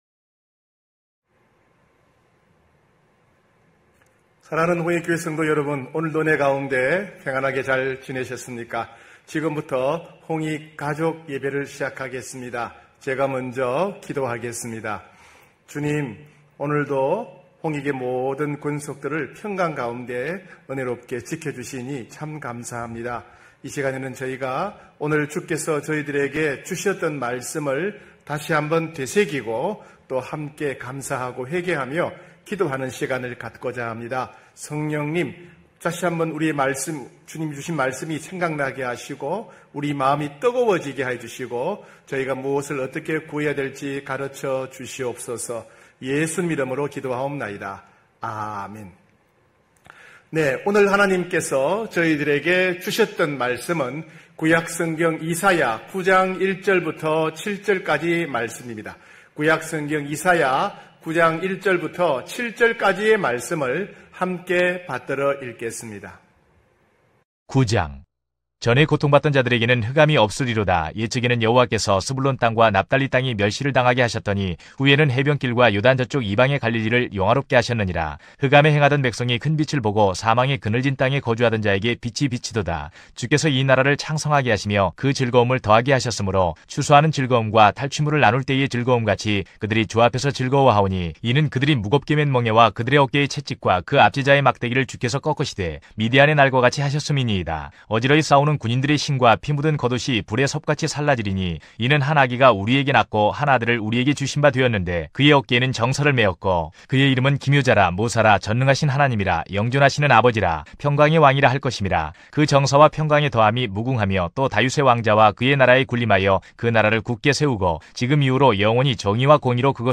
9시홍익가족예배(7월22일).mp3